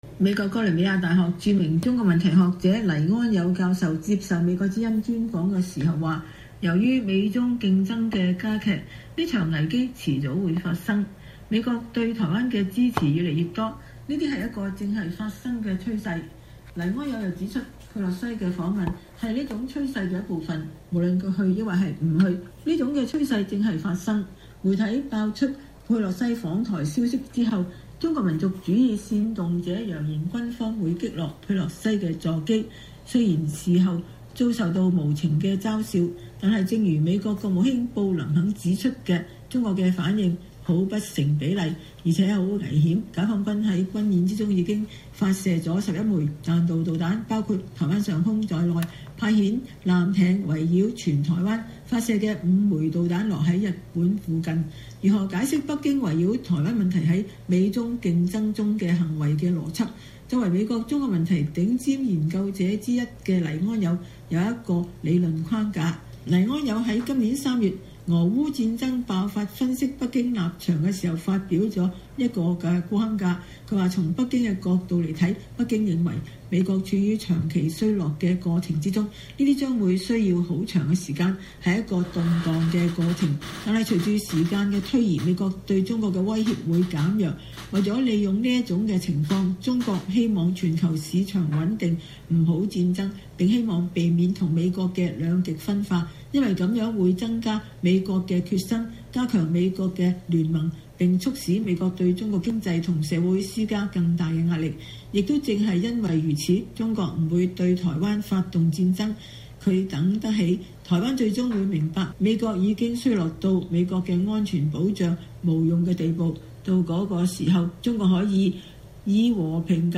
專訪黎安友：佩洛西訪台後 美中對抗將愈演愈烈 合作空間會越來越小
美國哥倫比亞大學著名中國問題學者黎安友教授接受美國之音專訪時說。